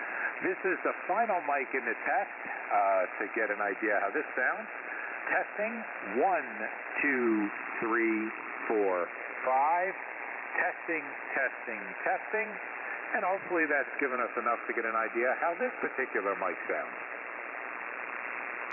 • Well rounded sound
• Full sound
• Sounds a bit like it is in a cave